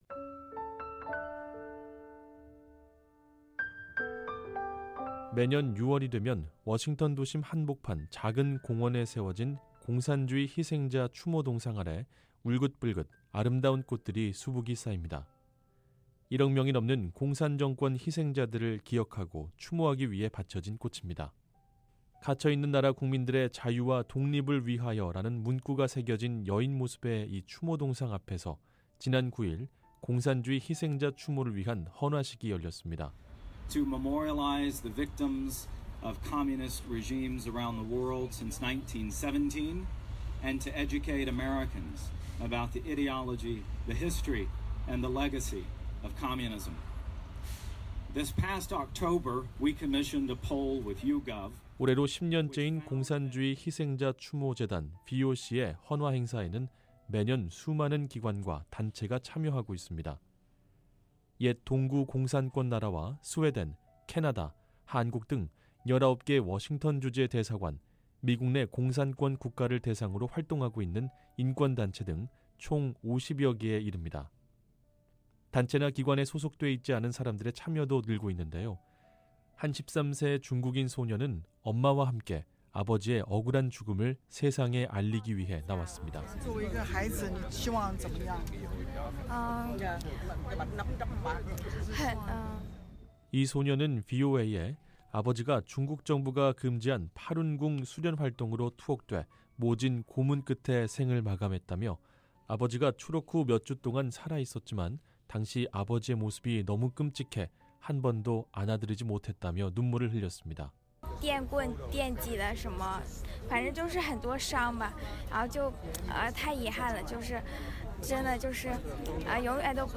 한 주간 북한 관련 화제성 뉴스를 전해 드리는 ‘뉴스 풍경’ 시간입니다. 미국 워싱턴에서는 매년 6월 전 세계 공산주의 희생자들을 기리는 추모 행사가 열리고 있습니다. 올해도 공산 정권을 경험한 수많은 사람들이 참석했습니다.